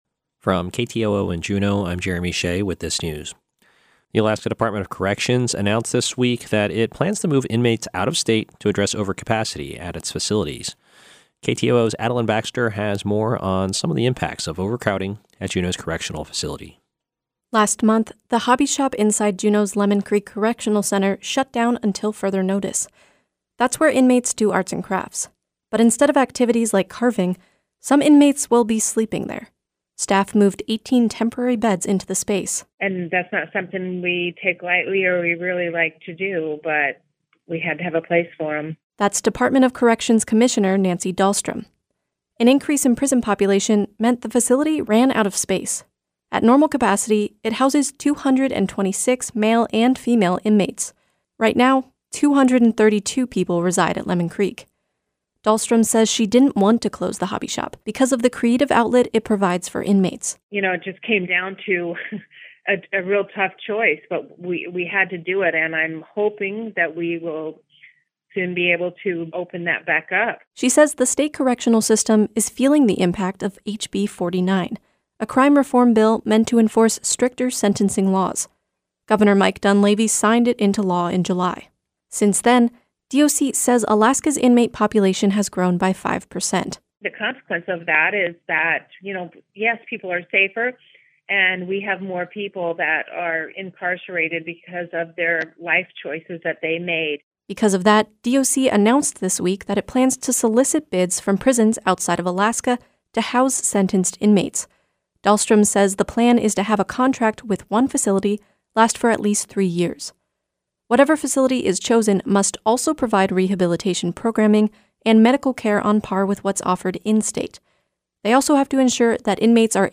Newscast – Friday, Oct. 18, 2019